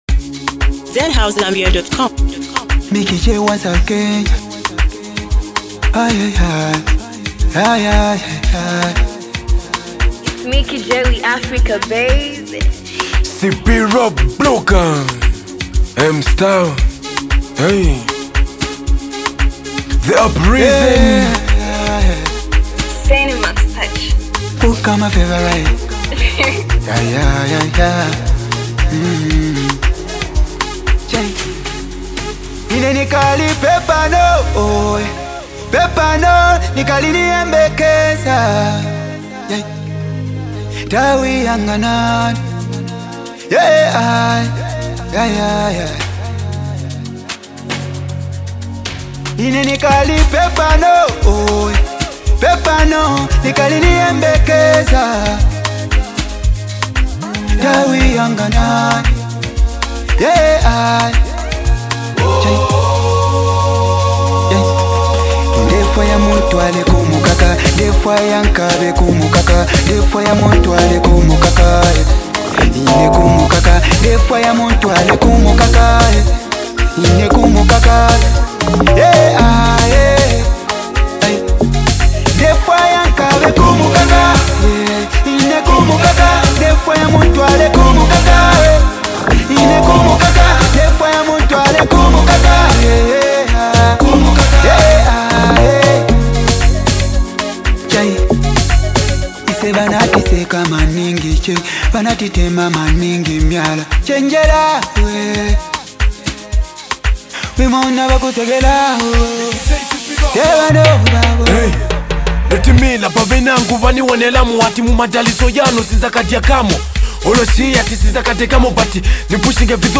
uplifting hip-hop track
motivational anthem